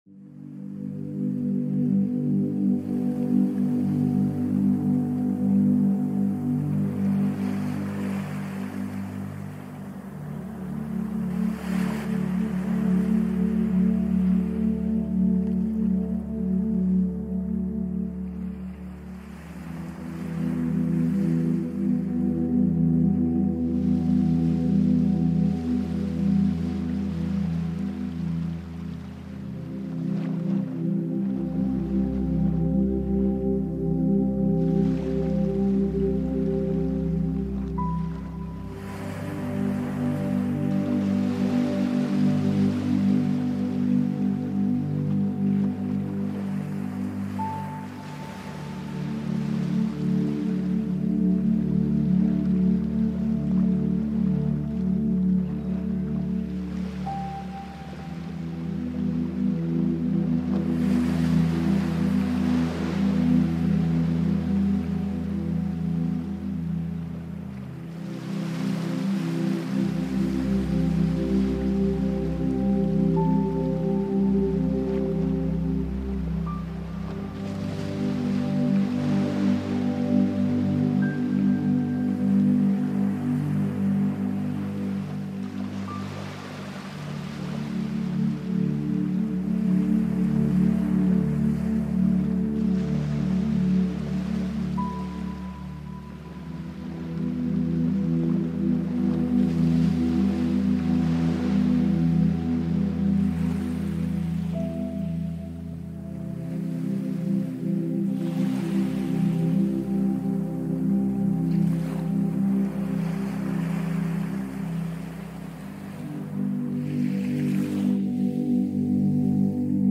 Fokus mit Regen-Geräuschen – Pomodoro-Timer mit Stadtansichten für maximale Produktivität
Es beginnt leise.